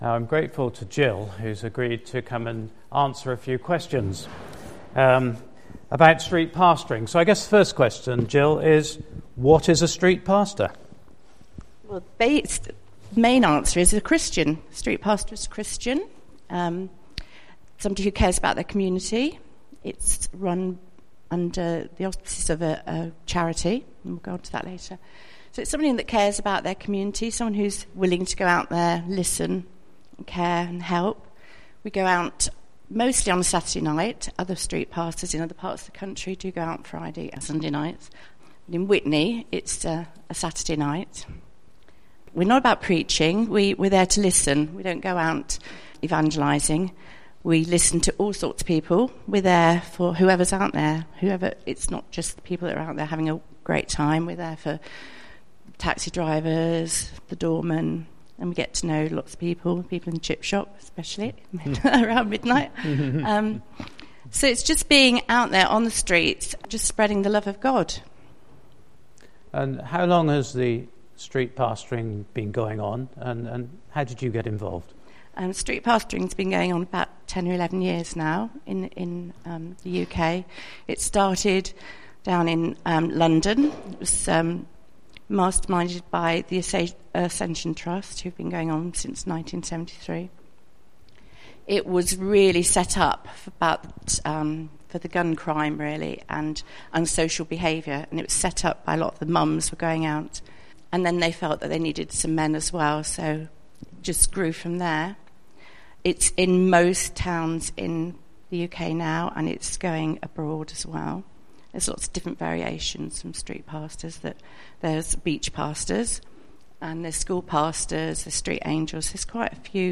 Today’s service celebrates Back to Church Sunday and there are four talks.